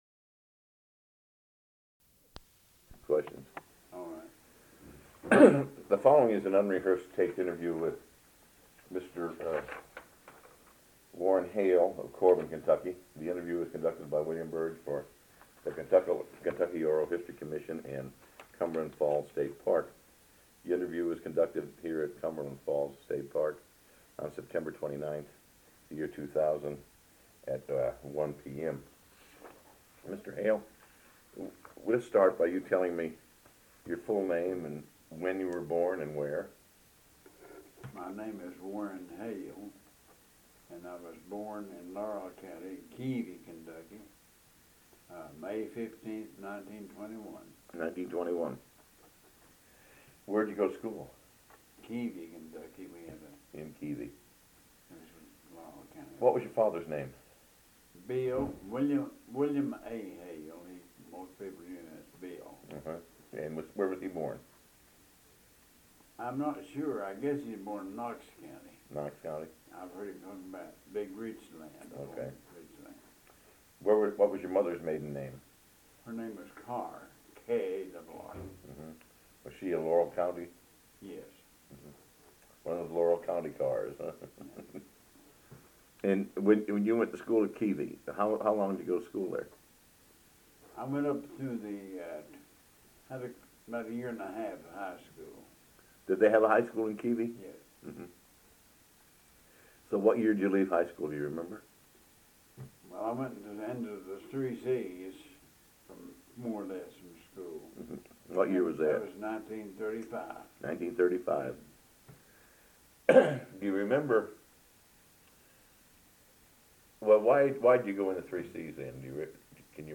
C.C.C. (Civilian Conservation Corps) Oral History Project